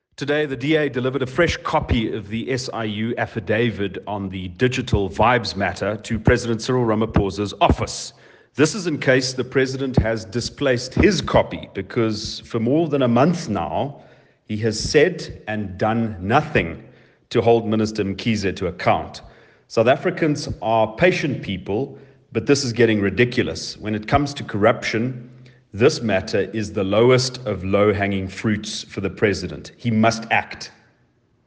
Afrikaans soundbites by Cilliers Brink MP.